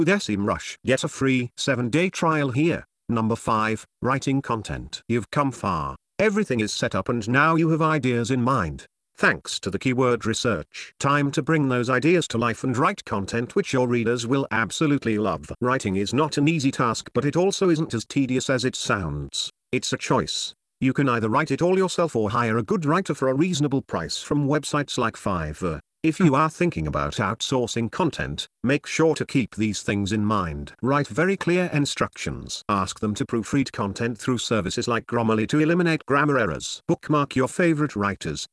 TTS Readers audio makes ears bleed and neck cringe
I’m now on Windows 10 and the audio has a background frequency we can’t figure out using the same software.
I didn’t hear any tonal problems, but there are some oddities.
I’m much more bothered by the odd metre and rhythm of the words. That’s the first indication to me that a machine is doing it.
The sample you’ve posted sounds OK to me, (just the occasional digital chirp).